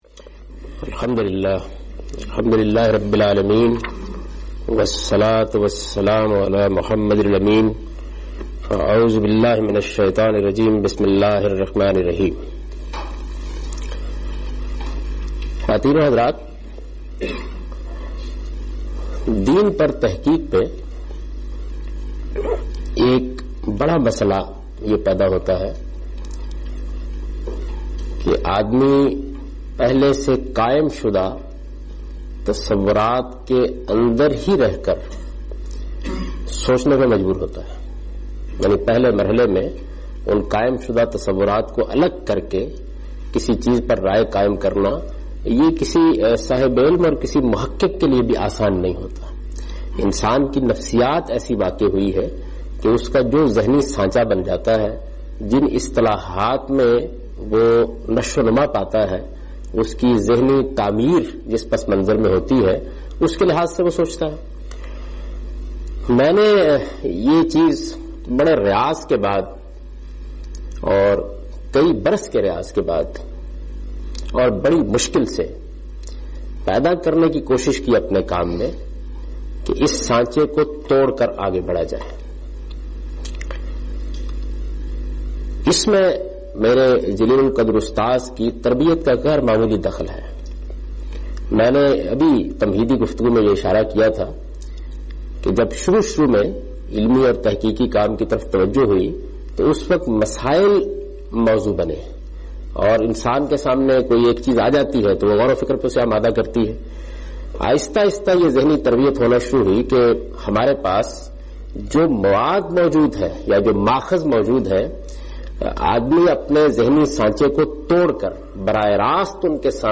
A comprehensive course on Islam, wherein Javed Ahmad Ghamidi teaches his book ‘Meezan’.
In this lecture he teaches basic principles on which he laid foundation of his whole work. (Lecture no.02 – Recorded on 10th January 2002)